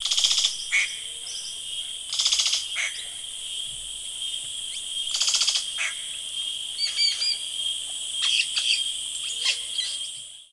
Blackbird With Ambience